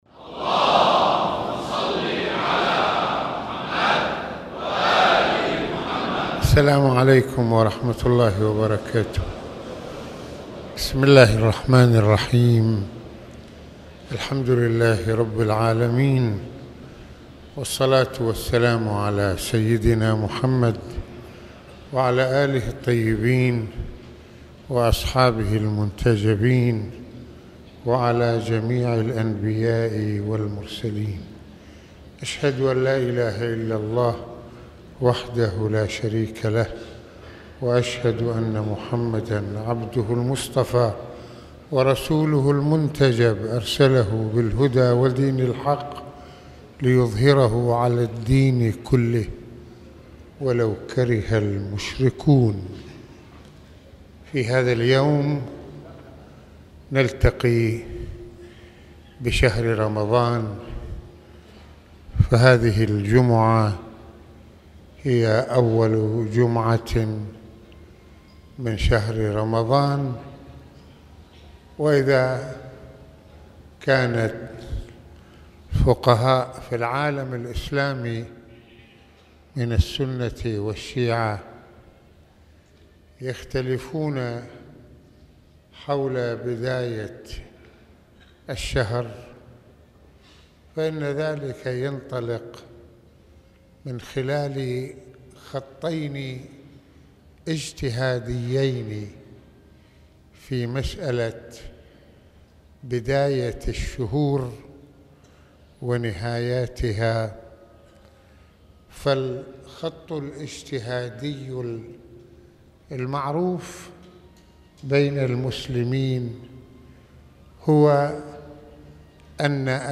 شهر رمضان تنمية للروح العبادية والأخلاقية | محاضرات رمضانية